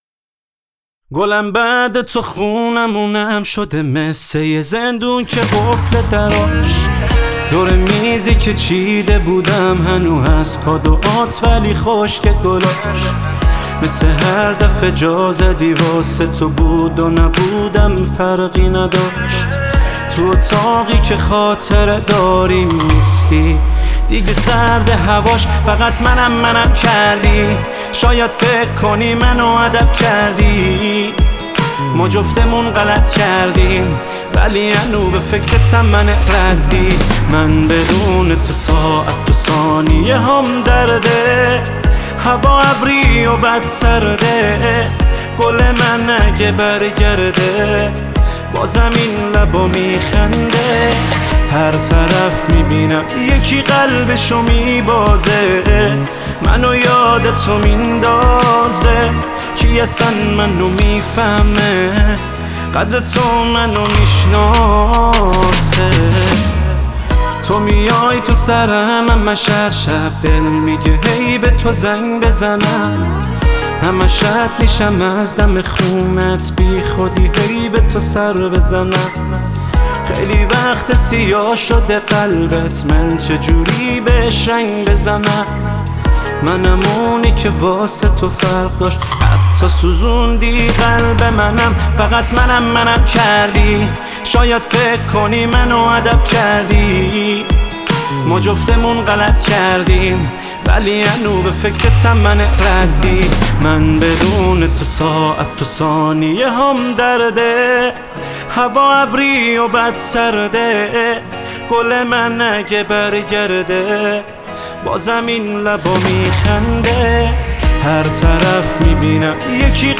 تک آهنگ
پاپ